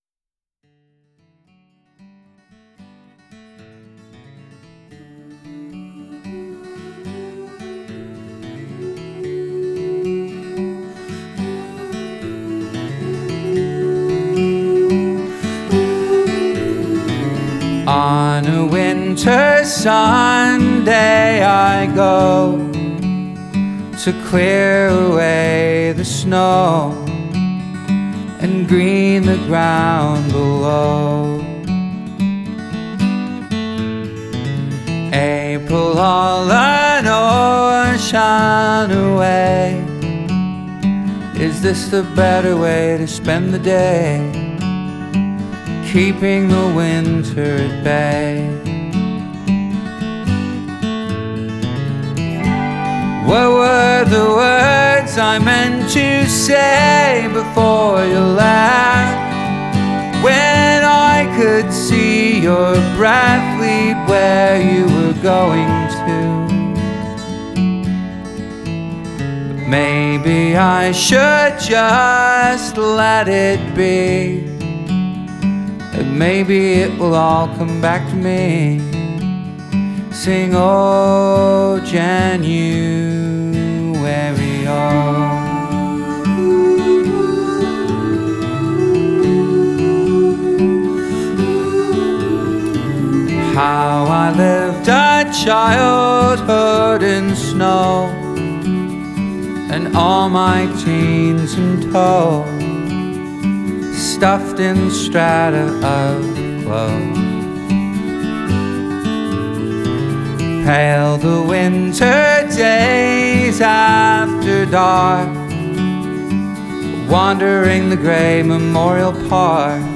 The album version is just as elegiacally gorgeous.